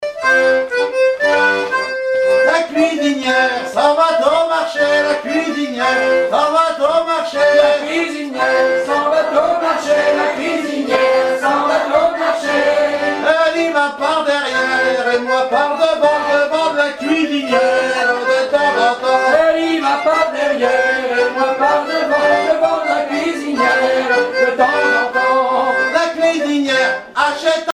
Genre énumérative
Pièce musicale inédite